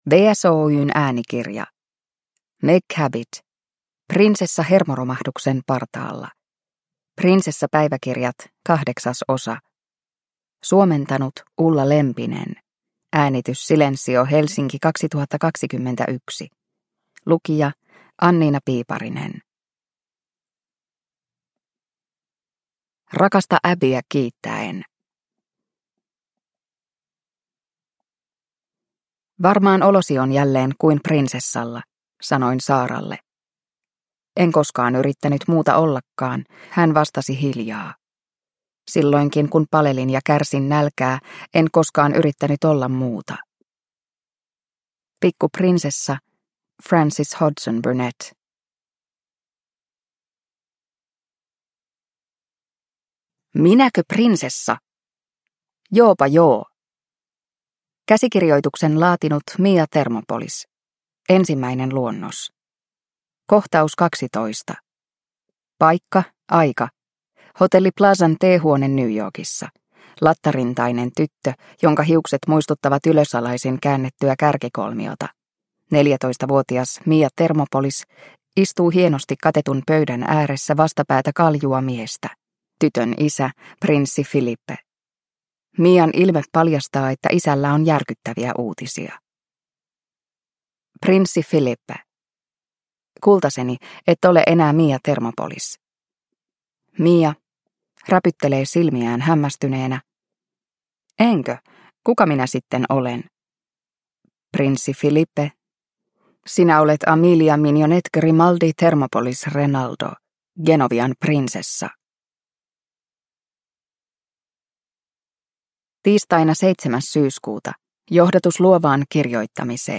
Prinsessa hermoromahduksen partaalla – Ljudbok – Laddas ner